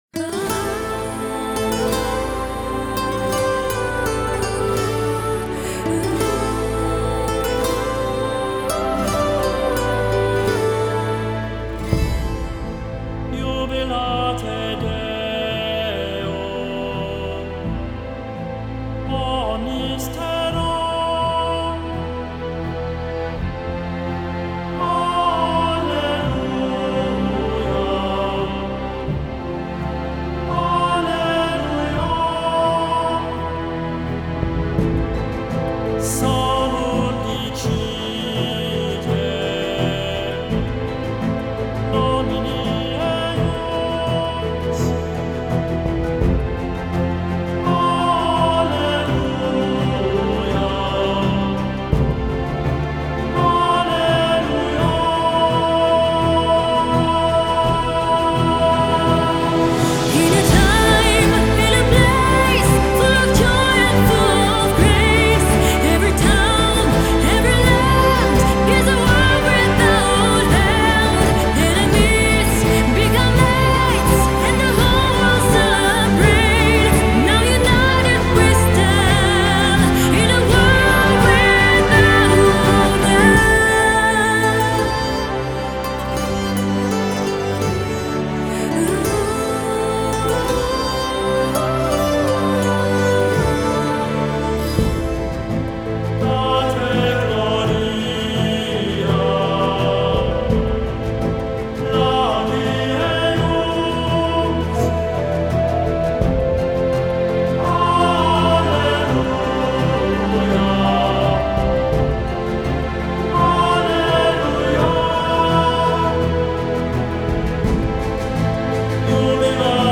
Genre: Pop, Chants